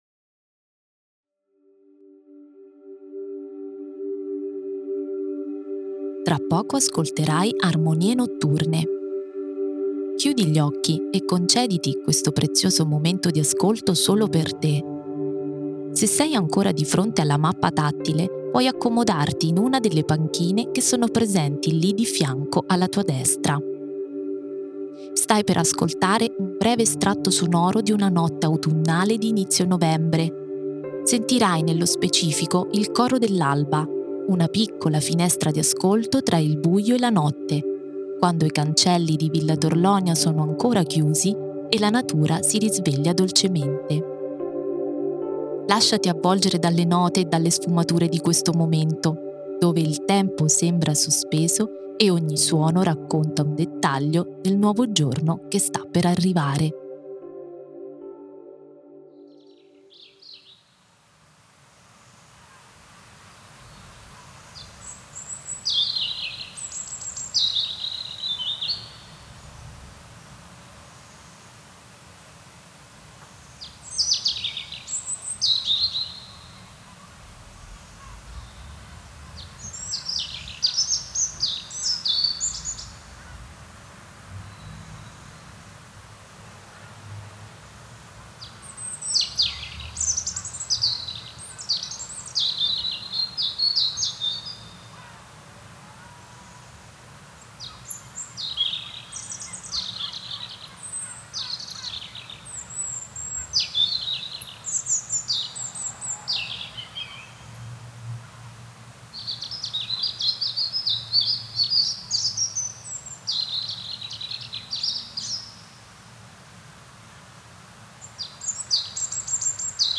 • “Armonie notturne” una preziosa finestra di ascolto, che ti permetterà di scoprire il paesaggio sonoro del parco al calar del sole.